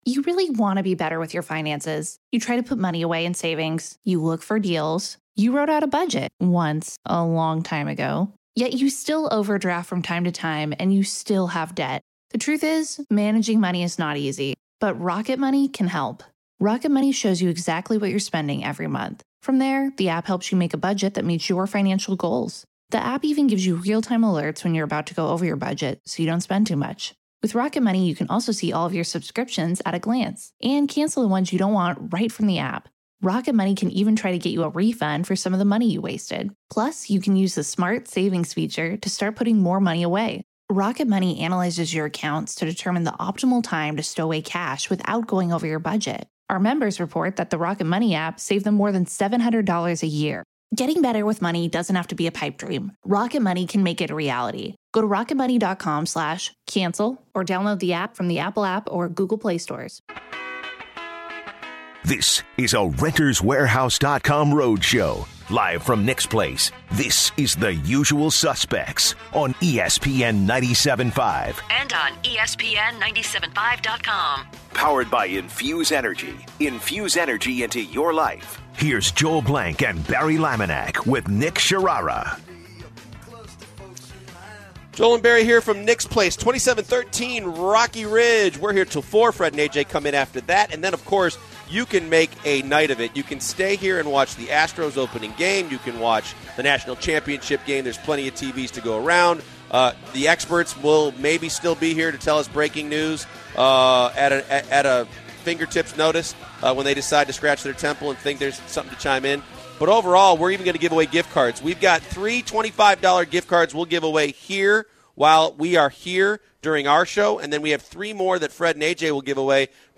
In the second hour the guys continue to talk about the Houston Rockets after their victory yesterday vs the suns and Bill Polian calls in to talk about Tony Romo and the NFL Draft.